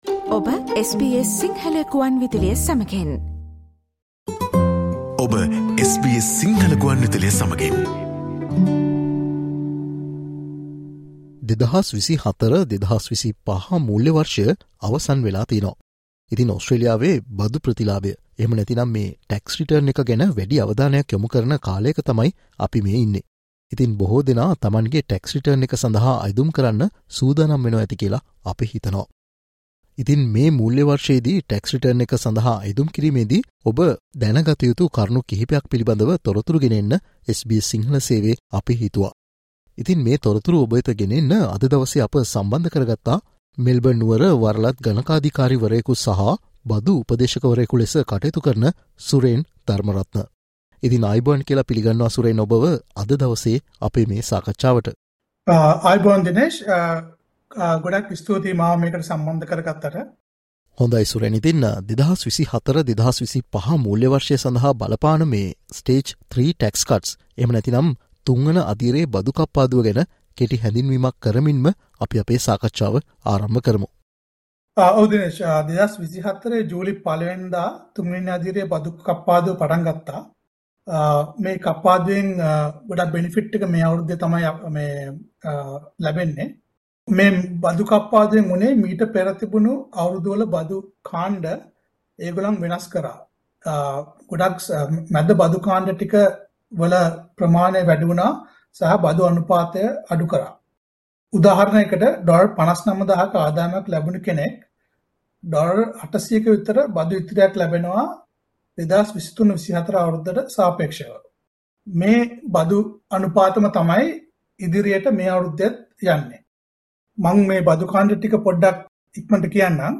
2024/2025 මුල්‍ය වර්ෂයේ සිට ක්‍රියාත්මක වන තුන්වන අදියරේ බදු කප්පාදුවේ වාසිය මෙවර බදු ප්‍රතිලාභය (Tax return) සඳහා අයදුම් කිරීම්දී බොහෝ ඕස්ට්‍රේලියානුවන්ට අත්දැකීමට ලැබෙනවා. මේ සම්බන්ධයෙන් සහ බදු ප්‍රතිලාභය සඳහා අයදුම් කිරීම්දී දැන ගතයුතු තවත් කරුණු කිහිපයක් මේ සාකච්චාවෙන් අපි ඔබ වෙත ගෙන එනවා.